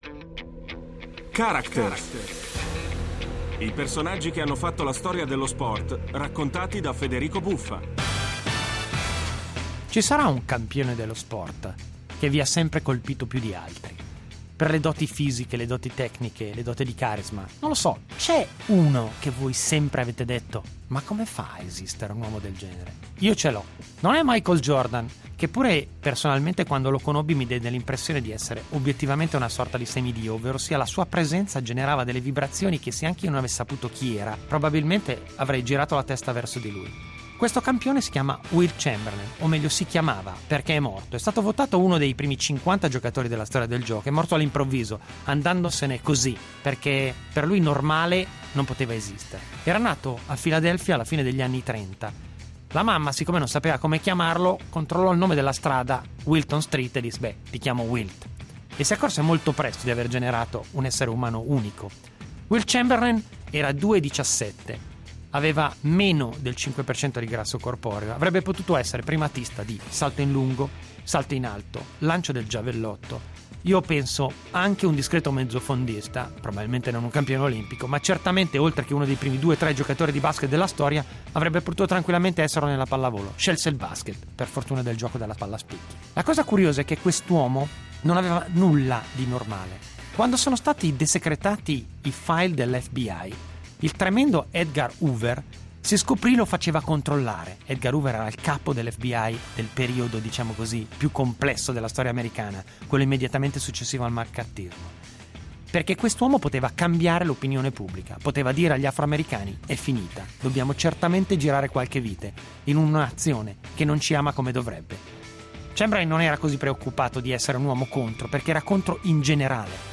Con Federico Buffa
L'incredibile vita di Wilton "Wilt" Norman Chamberlain, probabilmente il cestita NBA piiù dominante nella storia della pallacanestro, raccontata da Federico Buffa.